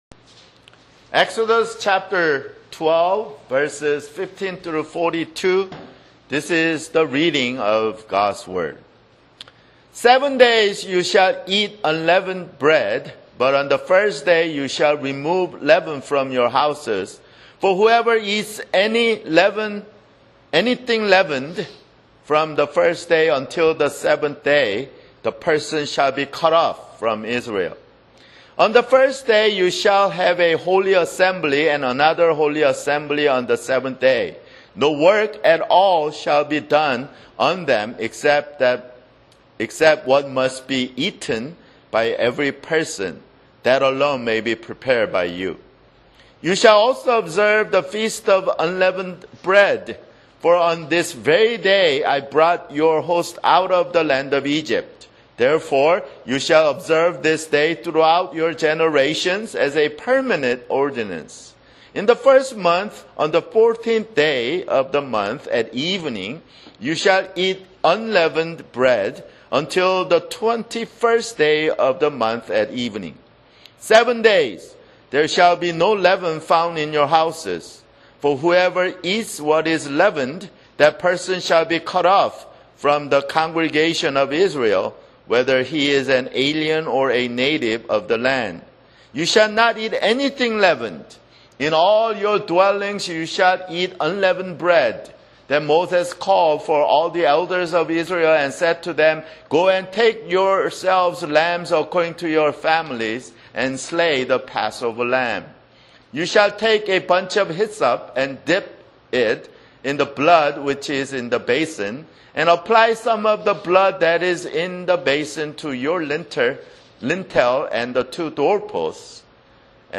[Sermon] Exodus (28)